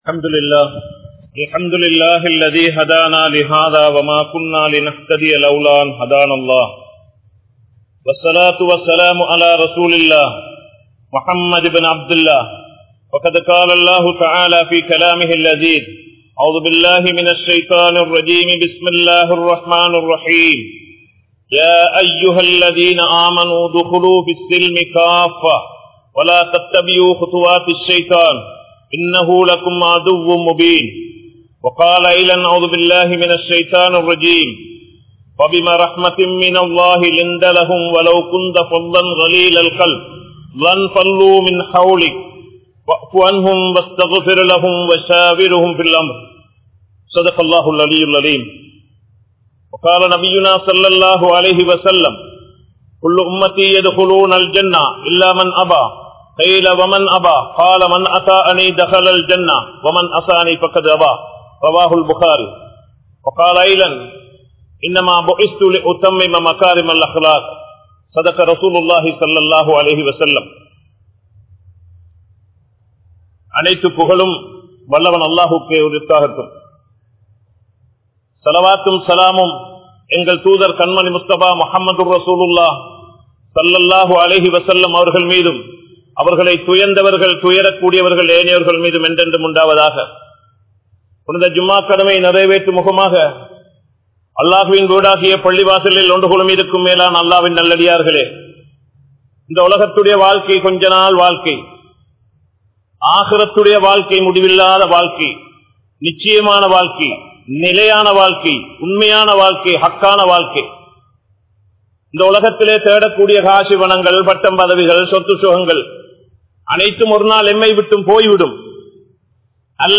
Indraya Muslimkalin Panpuhal (இன்றைய முஸ்லிம்களின் பண்புகள்) | Audio Bayans | All Ceylon Muslim Youth Community | Addalaichenai
Colombo 12, Grand Jumua Masjith